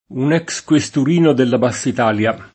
basso
un $kS kUeStur&no della baSS it#lLa] (Bianciardi); nell’800, anche in bass’uffiziale (ma più com. basso uffiziale), «sottufficiale» — il femm. sostantivato bassa («pianura più depressa»), anche con B- maiusc., in quanto sia inteso come top.: la b. (o B.) ferrarese — sim. il pers. m. Basso, oggi d’uso region. (molisano), e i cogn. Bassa, -si, -so, Del Basso, Della Bassa — cfr. abbasso; Da Basso; dabbasso; Li Bassi; Lo Basso